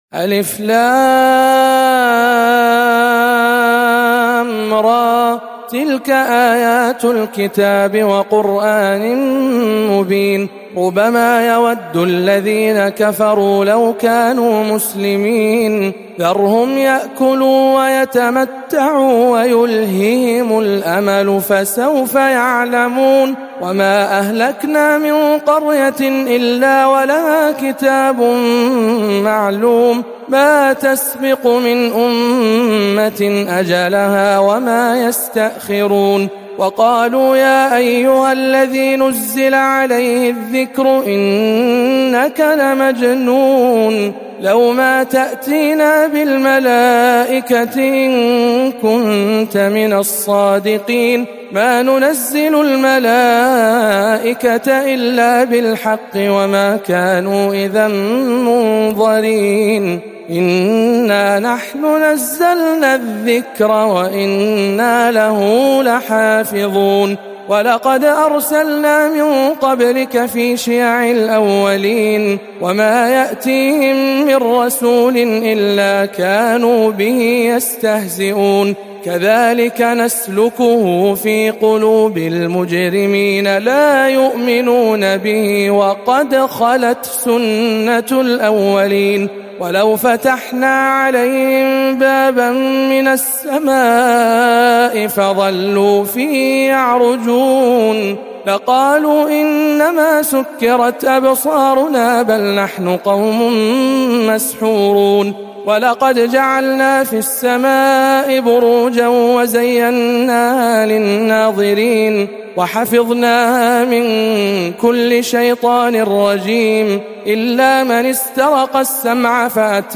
سورة الحجر بجامع أم الخير بجدة